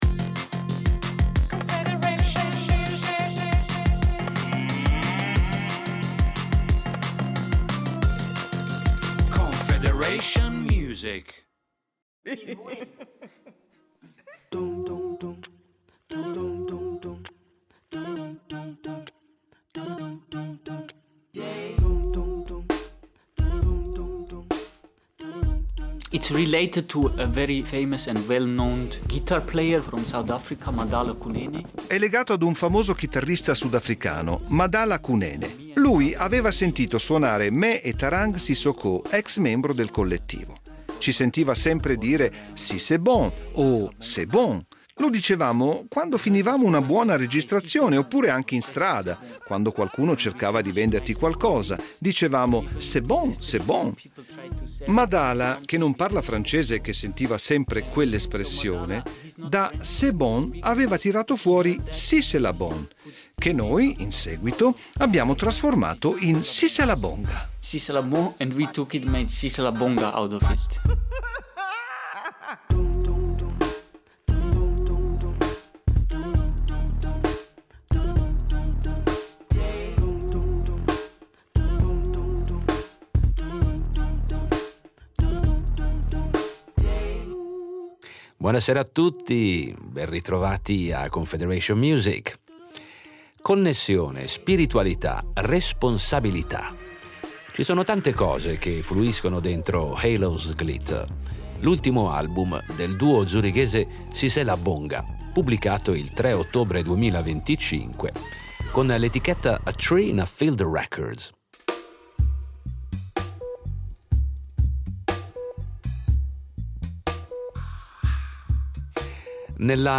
Musica etnica Siselabonga